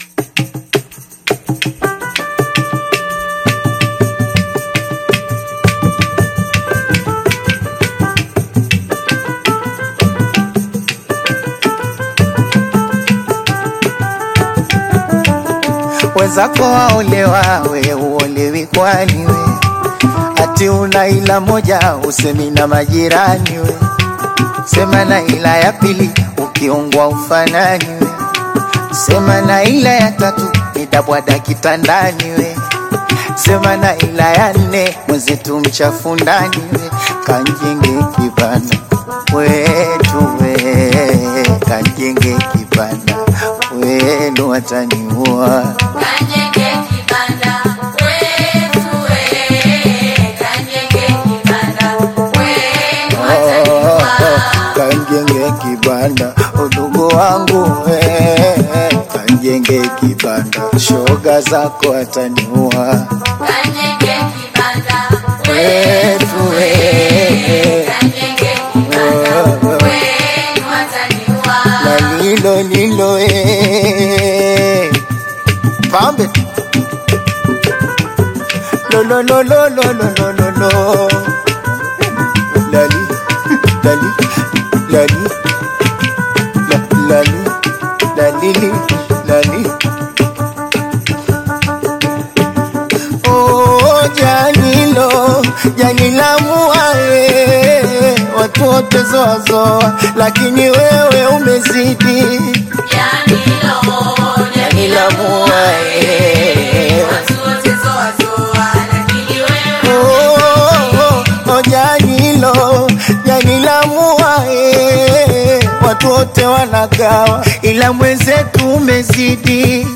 Get the Best East African music in high-quality Audio.
Afrobeats